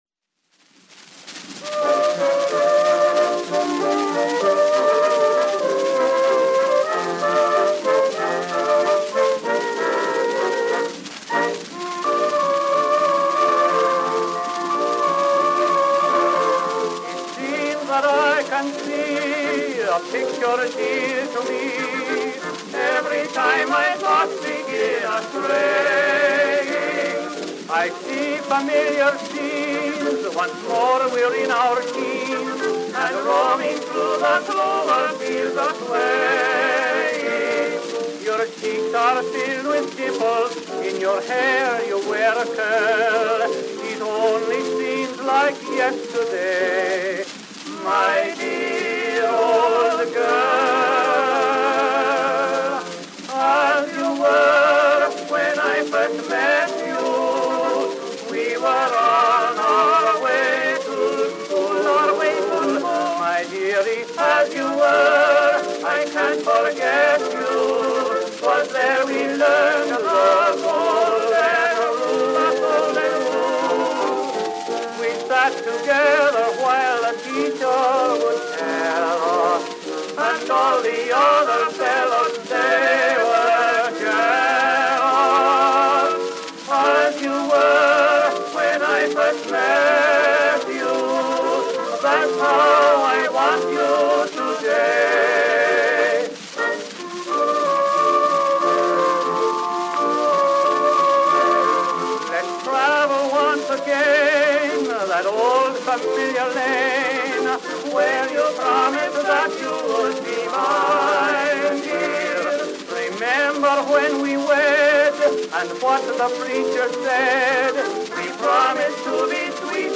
Tenor duet with orchestra accompaniment.
Popular music—1911-1920.